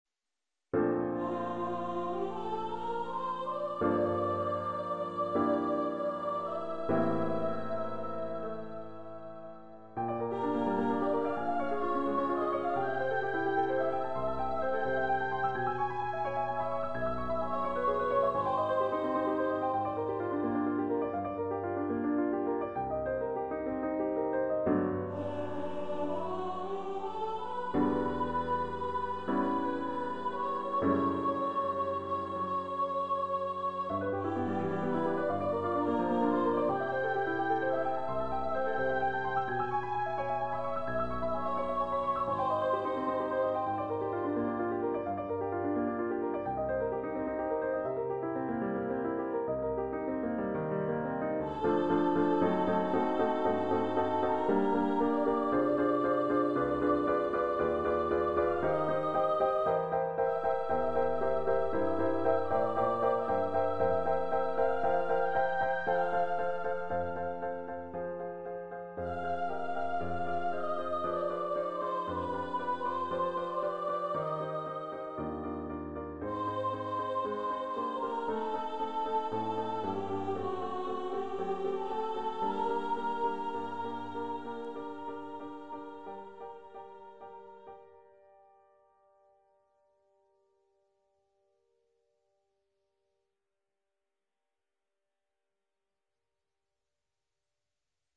Composer's Demo